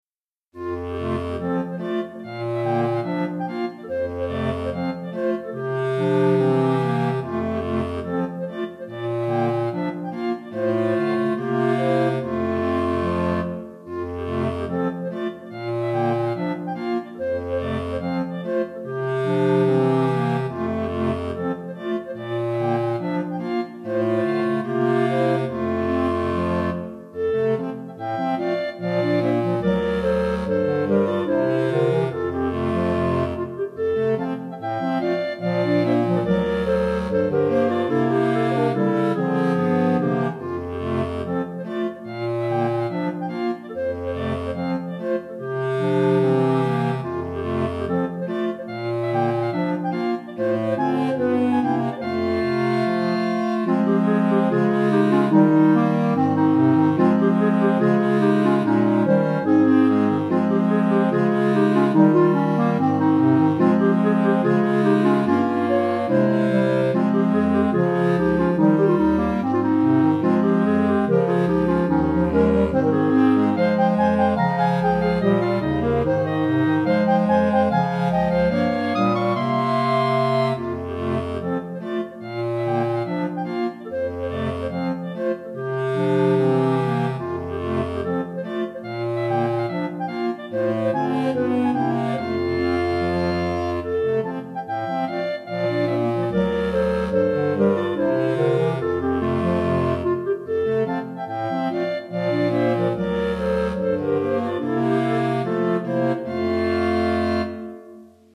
Répertoire pour Clarinette - 4 Clarinettes